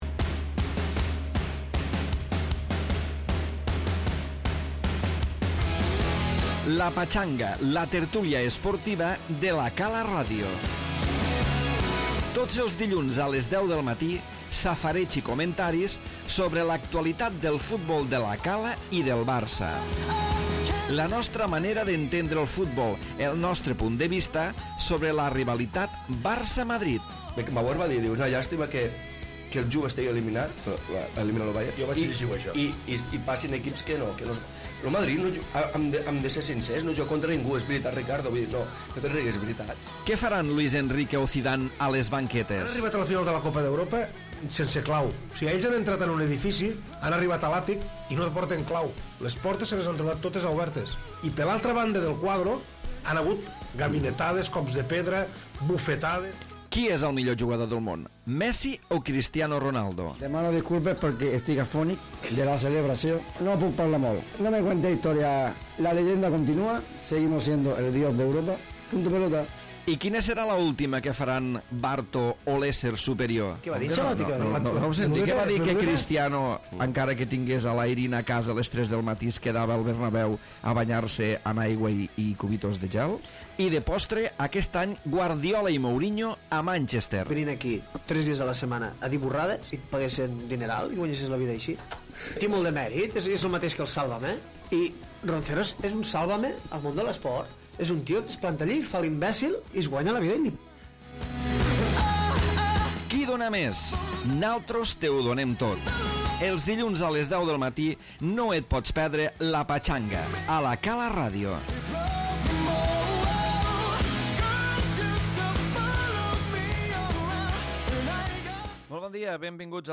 Tertúlia futbolística centrada en la delicada situació del Barça, després del partit de Champions davant del Paris Saint-Germain.